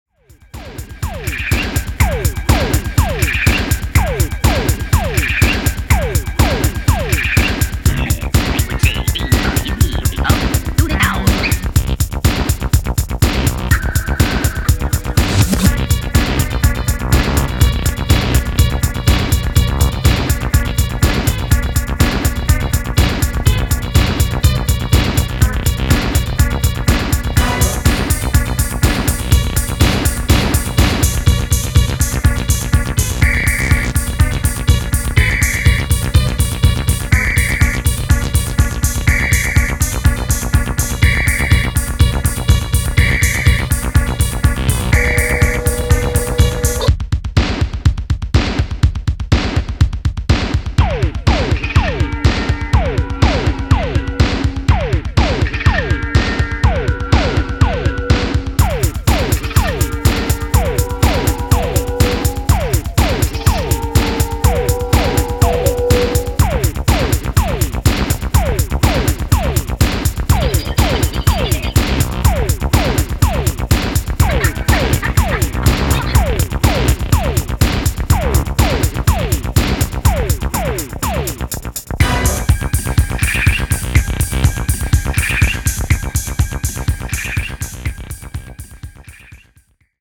Techno Acid Wave Italo